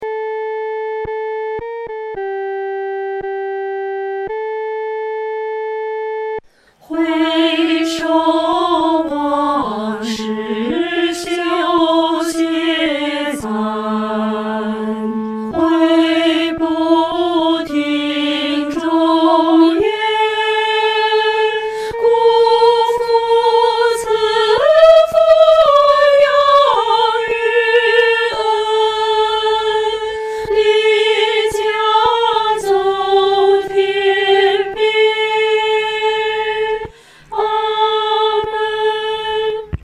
合唱
四声 下载
是距今800年前日本平安王朝 (794一 1192)就流行的一曲日本传统民间曲调